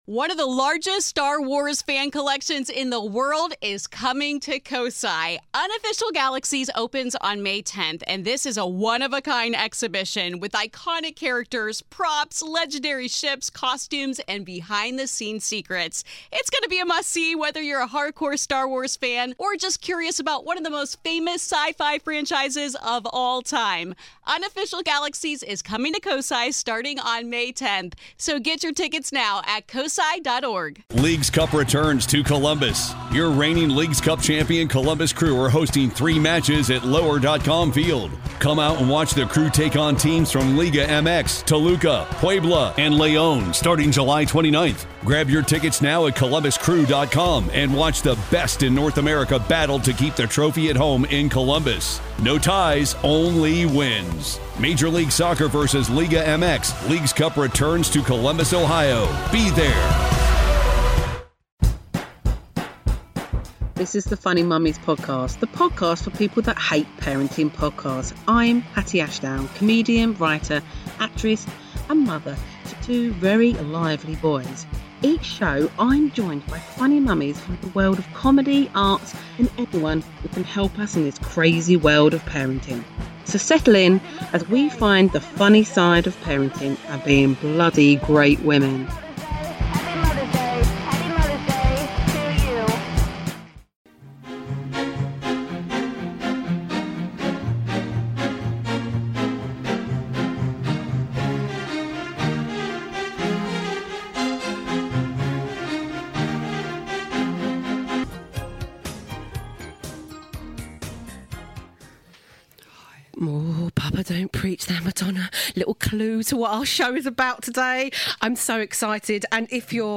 Recorded at Soho radio studios